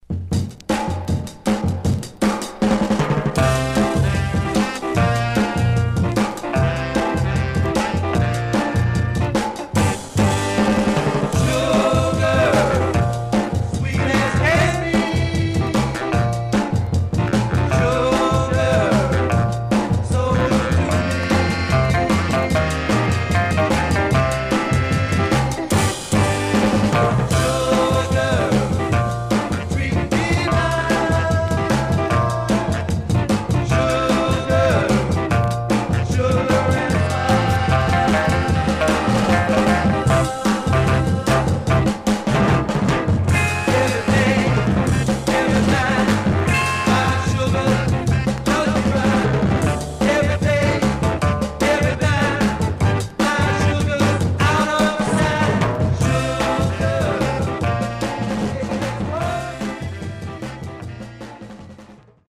Stereo/mono Mono
Rock